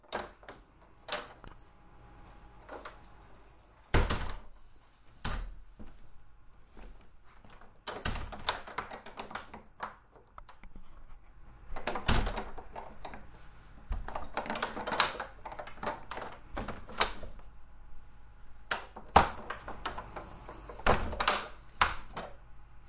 随机的 " 木质的门 粗糙的公寓入口双门，嵌有玻璃的门，打开关闭尴尬的门闩和警报声
描述：门木多节公寓入口双门与镶嵌玻璃打开关闭尴尬与闩锁和警报beep.flac
Tag: 开启 入境 关闭 木材 玻璃 蜂鸣报警 双层 镶嵌 公寓 粗糙